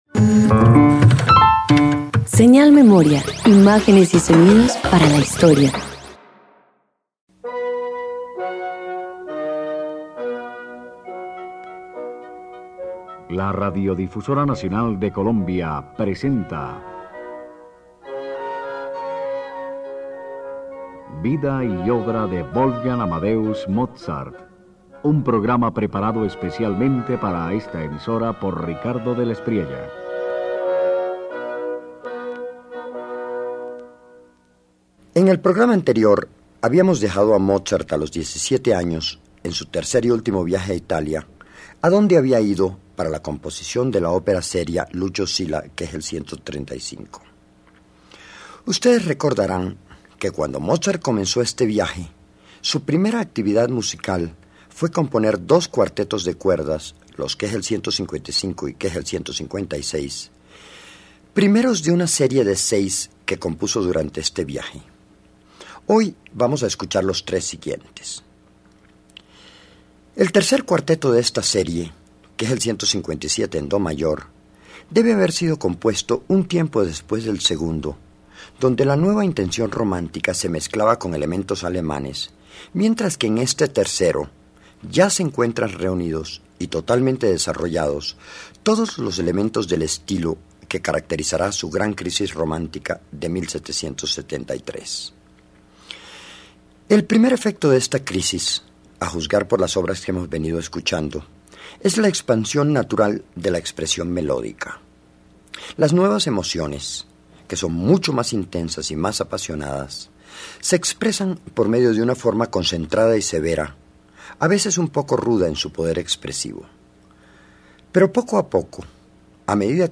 Con apenas 17 años, Mozart inicia una etapa de intensa búsqueda emocional con sus cuartetos de 1773. El Cuarteto en Do mayor K157 revela una voz expresiva, apasionada y profundamente italiana, donde la melodía anticipa la madurez de su genio musical.